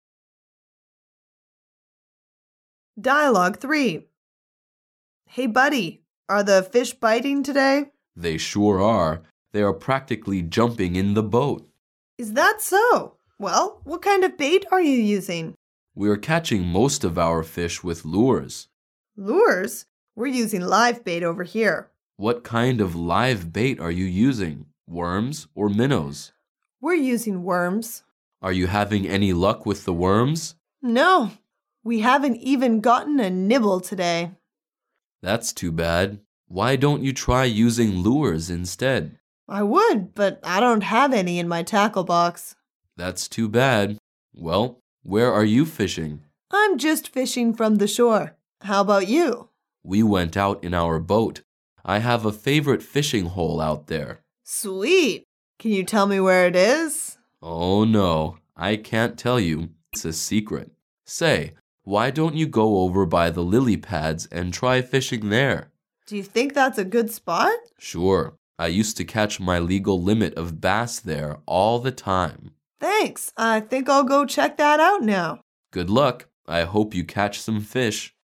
Dialouge 3